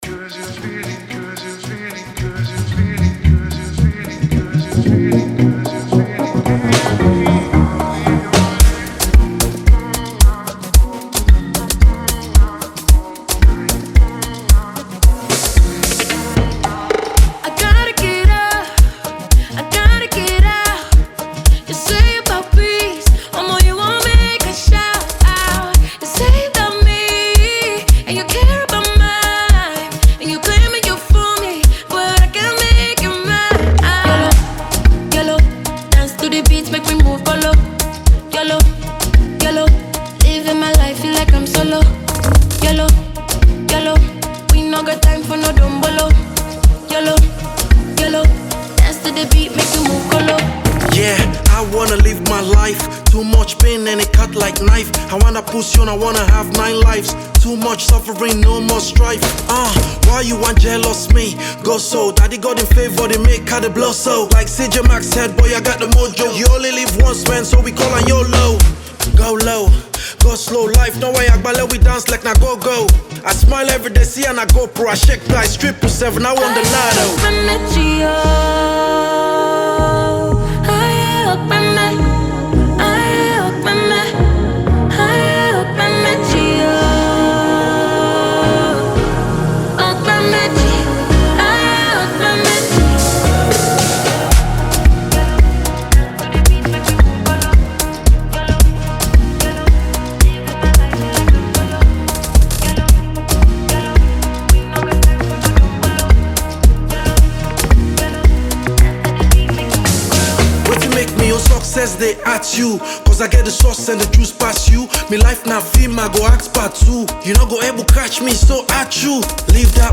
a famed hip-hop rapper from Sierra Leone